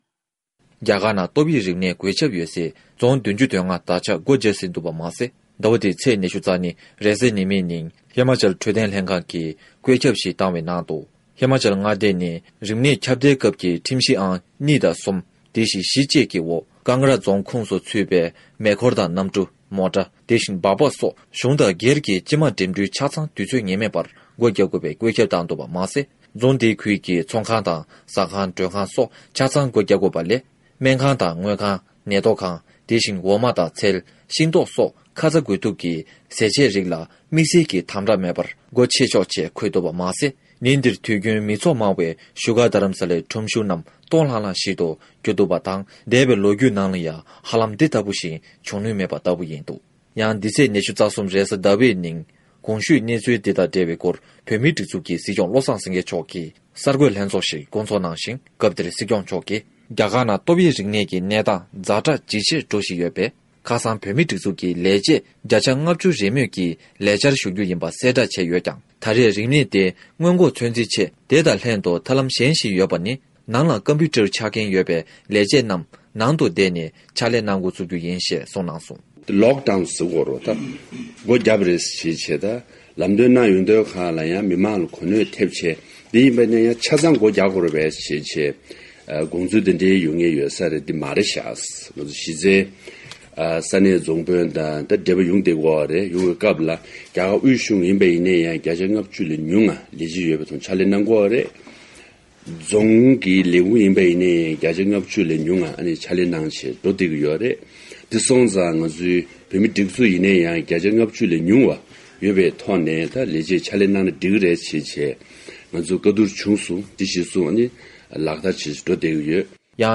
ས་གནས་ནས་བཏང་བའི་གནས་ཚུལ།